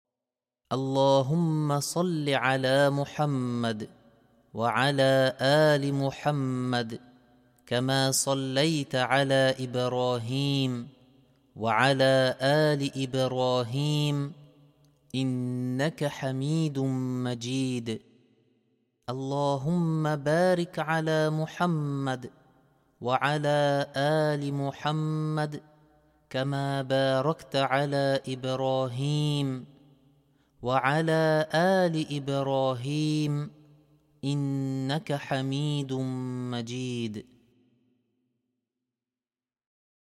Cliquez ci-dessous pour écouter la version complète de l’invocation en faveur du Prophète, appelée الصلاة الإبراهيمية as-salat al-‘ibrahimiyyah, à dire ici:
invocation-complet.mp3